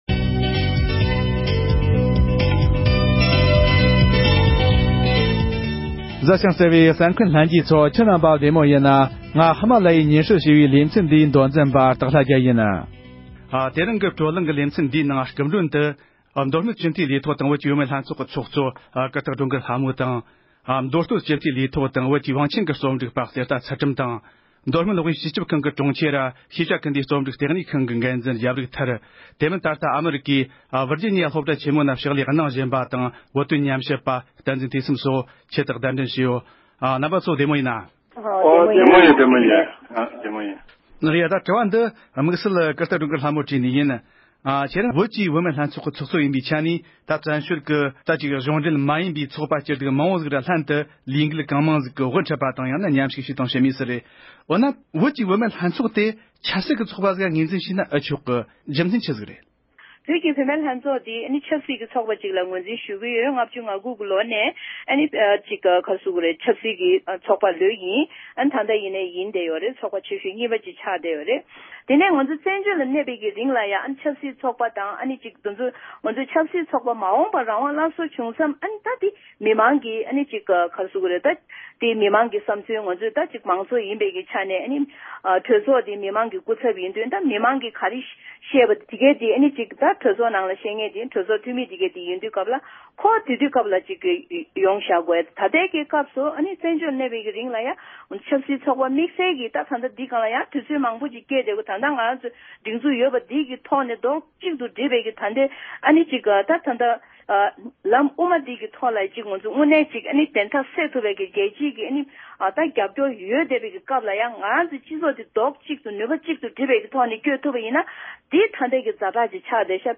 བཀའ་བློན་ཁྲི་པའི་འོས་མི་ནང་ཞུཊ་མཁན་རྣམས་ཀྱི་ཐུཊ་སྟོབས་དང་འབྲེལ་འབྱུང་འགྱུར་བཀའ་བློན་ཁྲི་པས་ཐུཊ་འགན་བཞེས་ཕྱོཊ་ཐད་བགྲོ་གླེང༌།